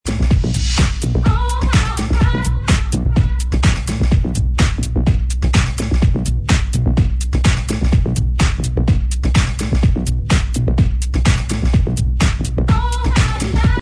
2006 progressive house track